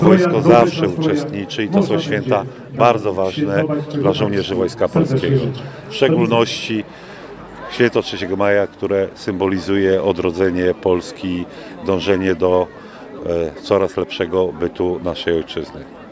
Uroczystości w deszczu